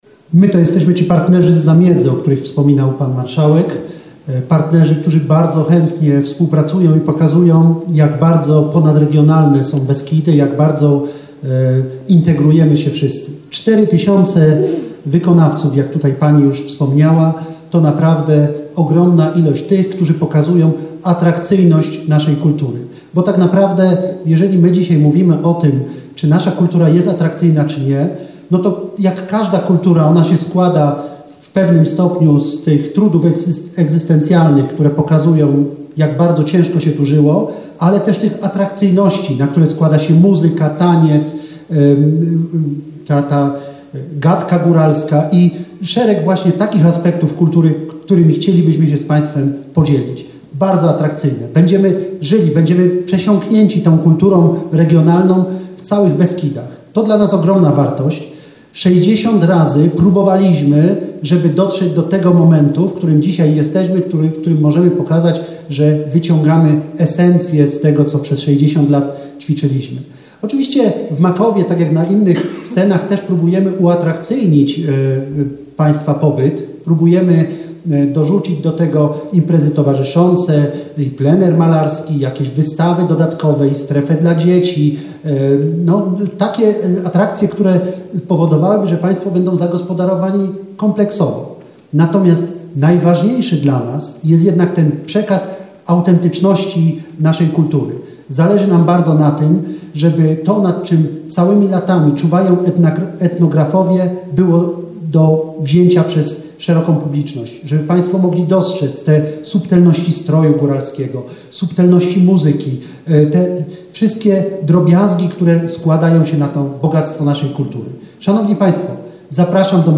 Reprezentanci Wisły, Szczyrku, Żywca, Oświęcimia, Makowa Podhalańskiego, Istebnej, Ujsół i Jabłonkowa na Zaolziu wystąpili dzisiaj podczas konferencji prasowej w Miejskiej Bibliotece Publicznej w Wiśle.